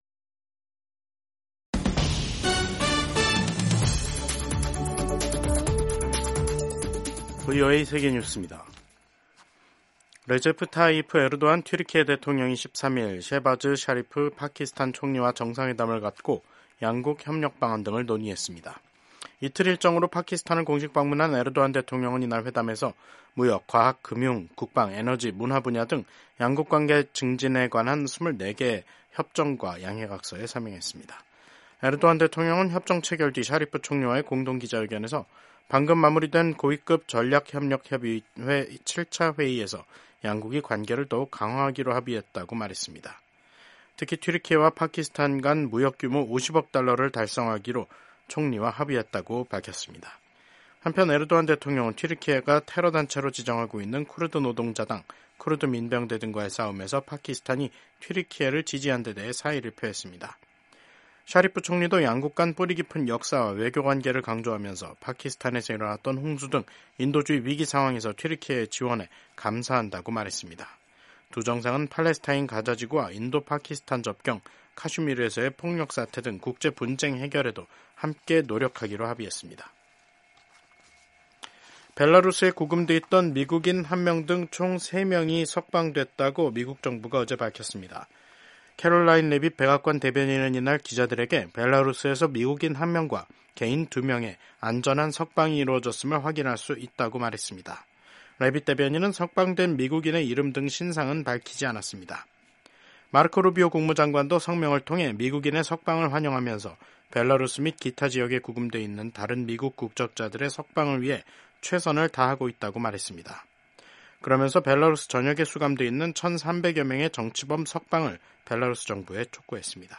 세계 뉴스와 함께 미국의 모든 것을 소개하는 '생방송 여기는 워싱턴입니다', 2025년 2월 13일 저녁 방송입니다. 도널드 트럼프 미국 대통령이 우크라이나 전쟁 종식을 위한 본격적인 외교에 나섰습니다. 트럼프 대통령은 12일 블라디미르 푸틴 러시아 대통령과 볼로디미르 젤렌스키 우크라이나 대통령과 잇달아 통화하고 협상 착수를 지시했습니다. 도널드 트럼프 미국 대통령이 13일 백악관을 방문하는 나렌드라 모디 인도 총리와 정상회담을 갖습니다.